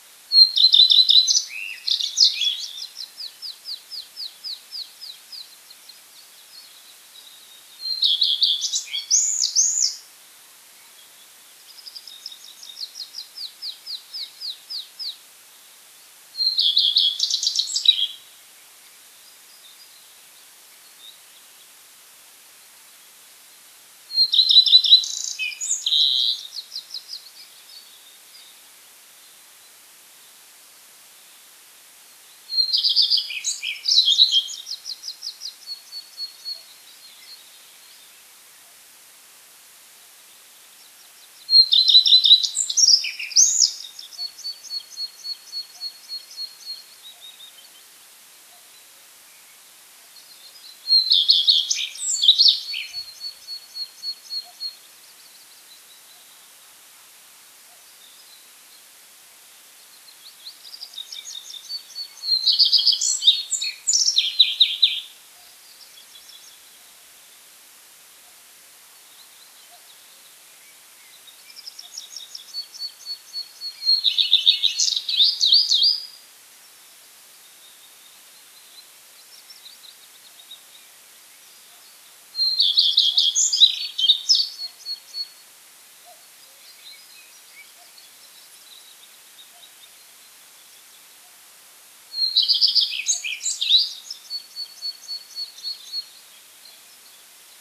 горихвостка-лысушка, Phoenicurus phoenicurus
Administratīvā teritorijaBaldones novads
СтатусПоёт